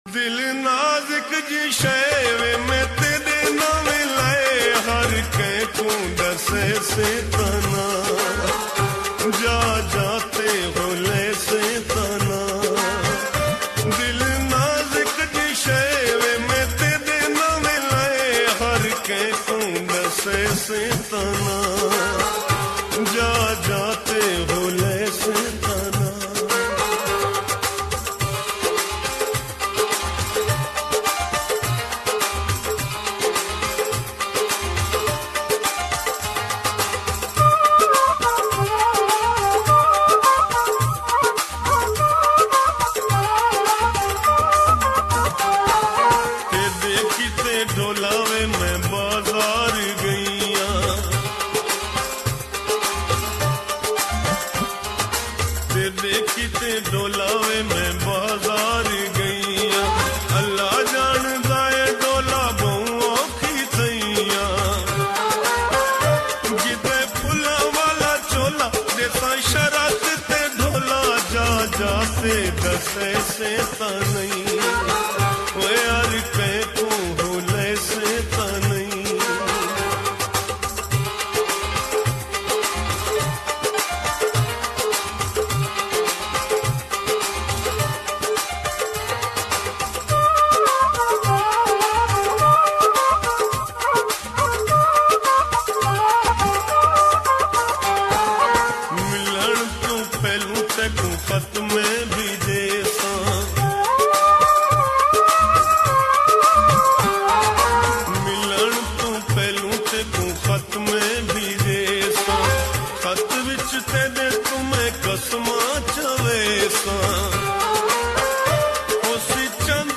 𝐒𝐋𝐎𝐖 ♡ 𝐑𝐄𝐕𝐄𝐑𝐁